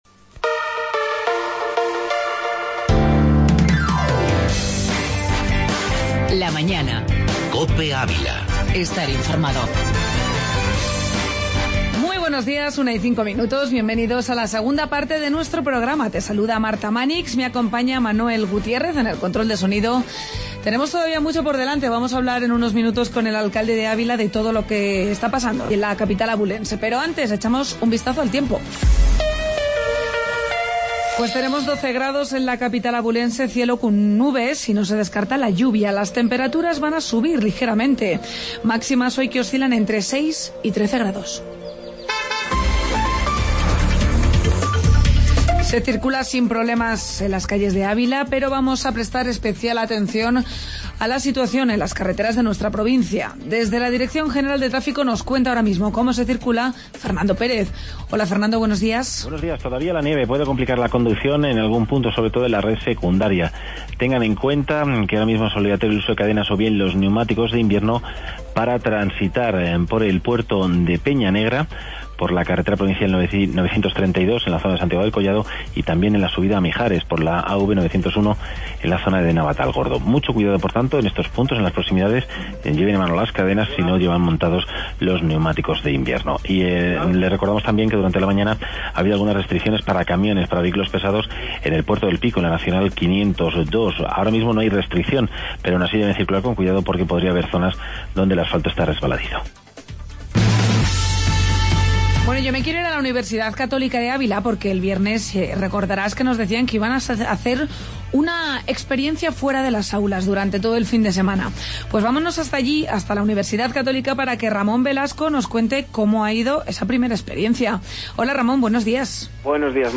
AUDIO: Entrevista alcalde de Ávila, José Luis Rivas